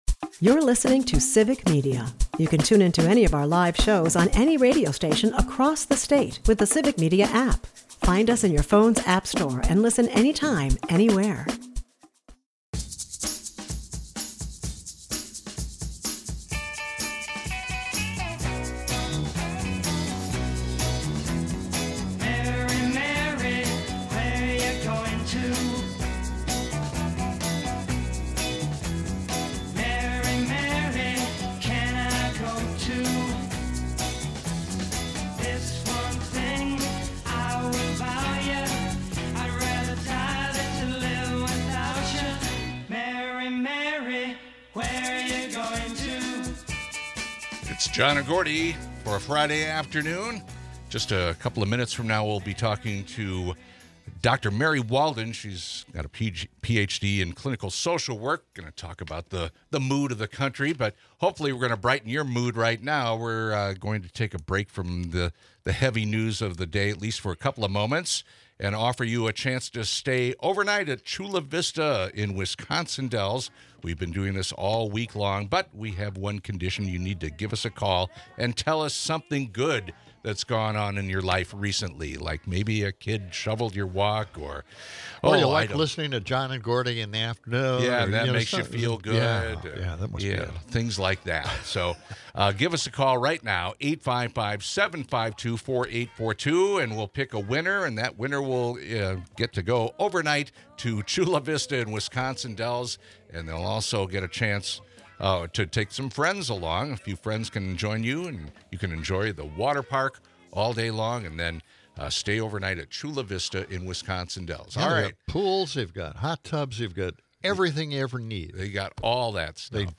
The hosts and callers discuss a nationwide protest against ICE, reflecting on historical and current civil unrest. A Wisconsin Republican's push to loosen concealed carry laws sparks heated debate, with a physician caller advocating for responsible gun ownership. Amidst this, a protest outside the studio adds a live-action backdrop to the discussion, highlighting the chaotic yet engaging nature of the current news landscape.